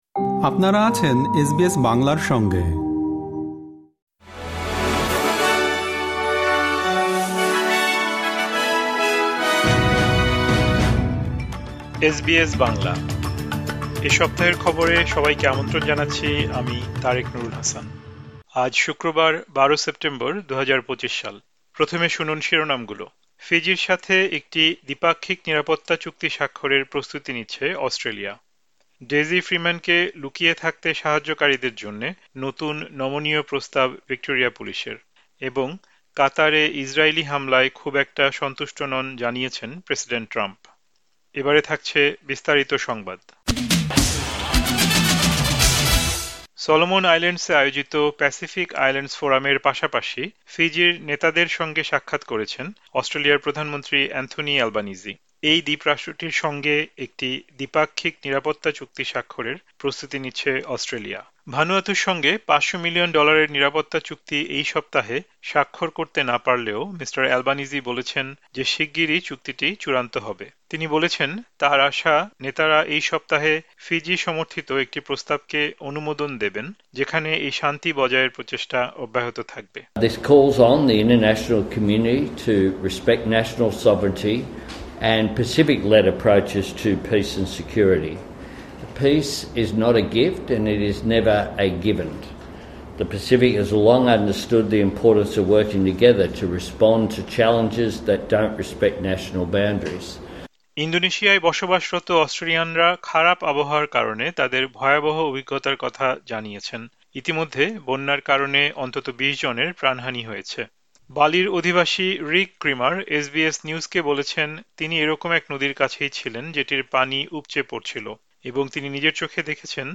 এ সপ্তাহের খবর: ১২ সেপ্টেম্বর, ২০২৫